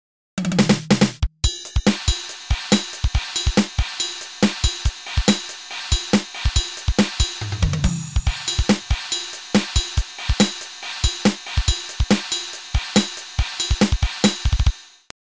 This sample is more complete than most, with an intro and outro, because I put it together for my answering machine. The basic rhythm features a ride pattern split over 3 sources, played in an even time, so that the ride pattern takes three bars to resolve.
The snare is totally straight, 2 and 4 with no ghosting.
The kick is somewhat interesting but not so much that I’m going to write anything about it.